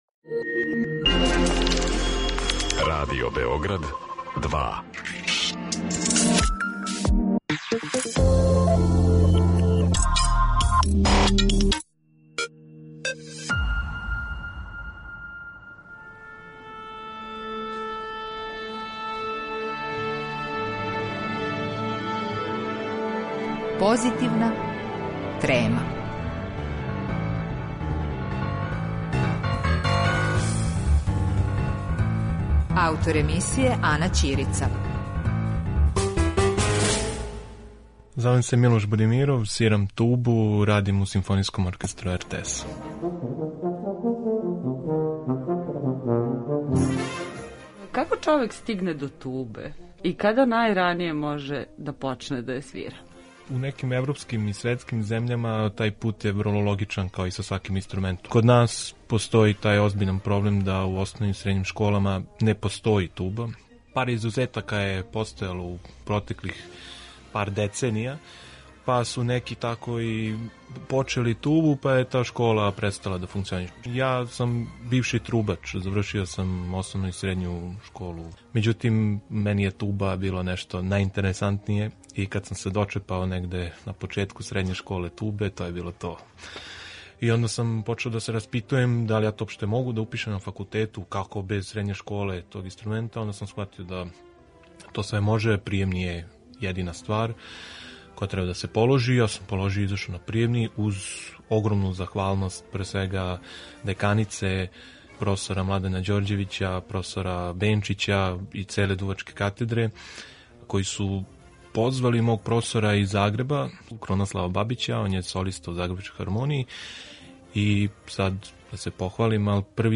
Разговор о туби